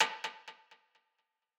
Snares
{Snr} TTLN.wav